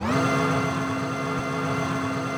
chargeLoop.wav